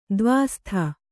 ♪ dvāstha